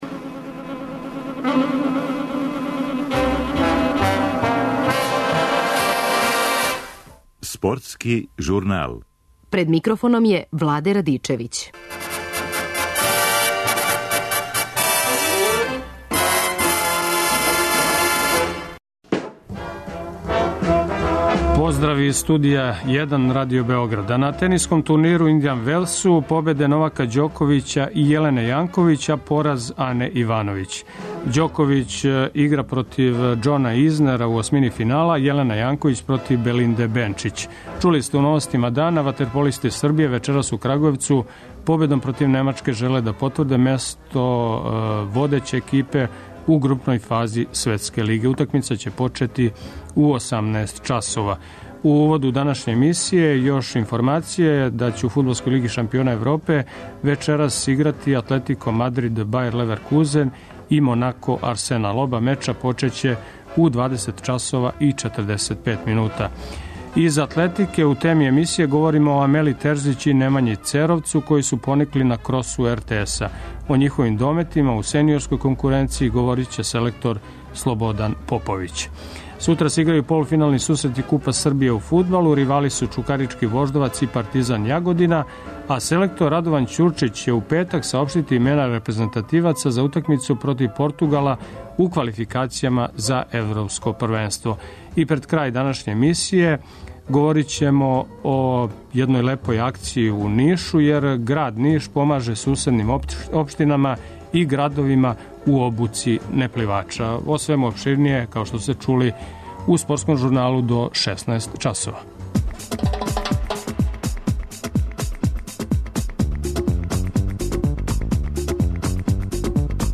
Утакмица ће почети у 18 часова уз повремена укључења нашег репортера.